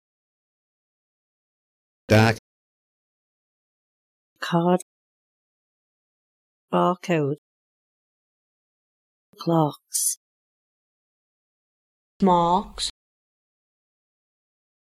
The following examples are extracted from the Liverpool informants. Each example contains words with the same vowel from all informants, arranged from oldest to youngest.
Example 2: Liverpool. Words with the START vowel:
00liverpoolstart.mp3